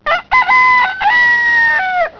rooster.wav